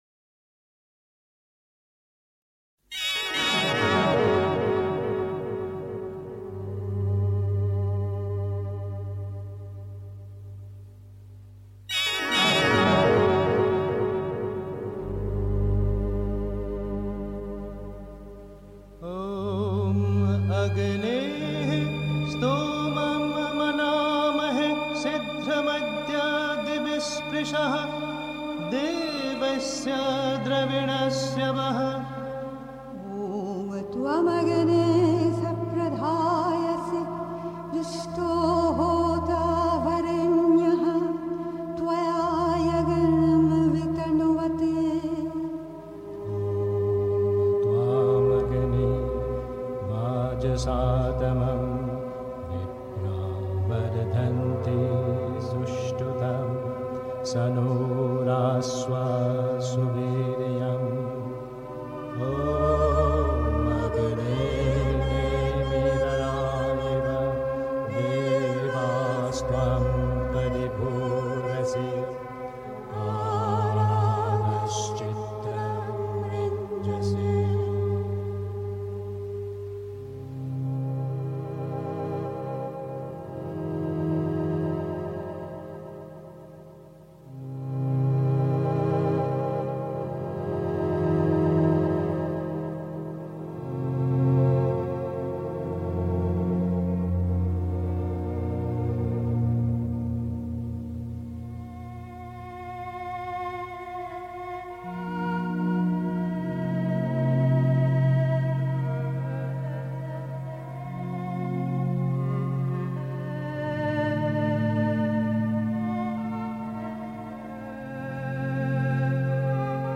März 1958) 3. Zwölf Minuten Stille.